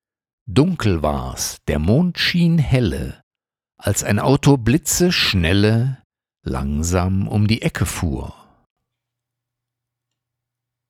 Aufnahme nach der Bearbeitung mit Audacity
TDR-Nova (dynamischer Equalizer), TDR-Kotelnikov (Kompressor) und Lisp (De-Esser) sind als freie VST-Plugins verfügbar.